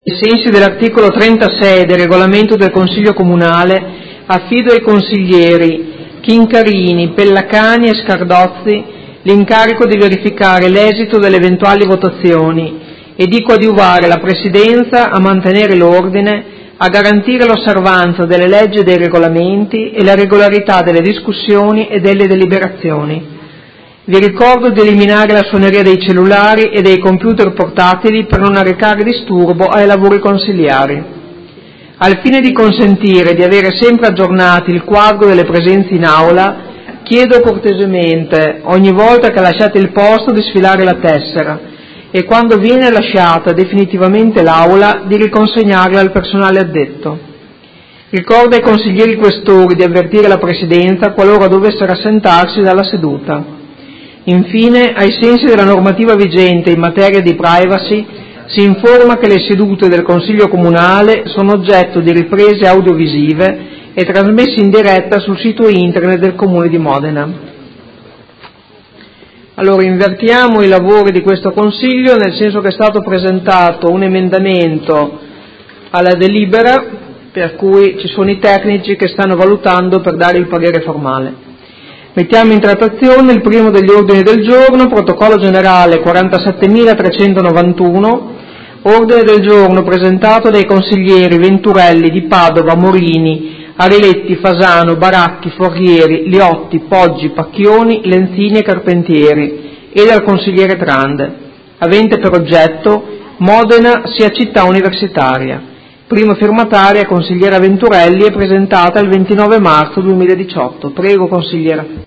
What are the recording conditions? Seduta del 10/05/2018 Apre i lavori del Consiglio Comunale.